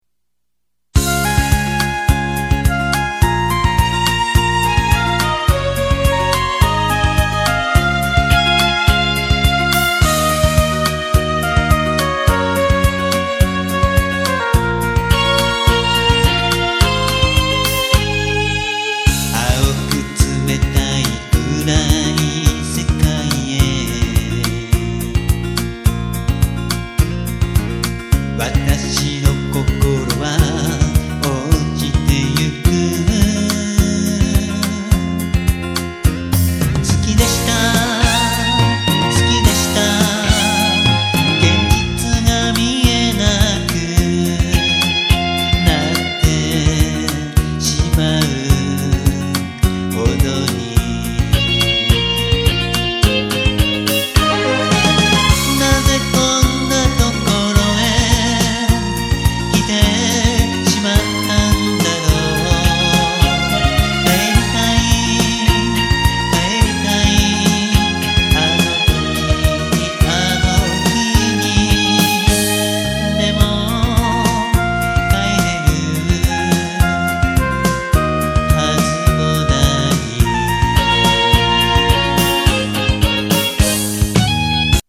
ワンコーラス　　ＭＰ３ファィル・・・1.53ＭＢ